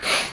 Descarga de Sonidos mp3 Gratis: olfatear.
snif-3.mp3